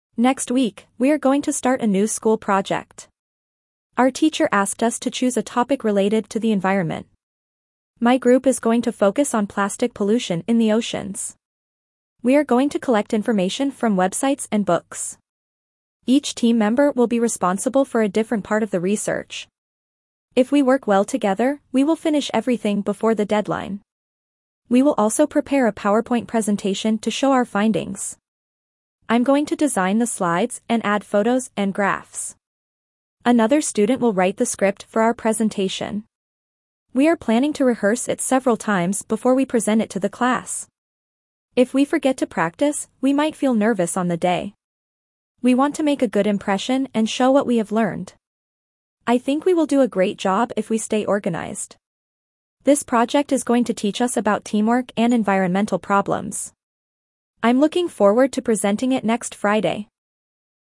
Dictation B1 - School Project
Your teacher will read the passage aloud.
4.-B1-Dictation-School-Project.mp3